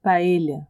paella /paelha/
Na Espanha a pronúncia do LL costuma ser parecido com o som de “LH”.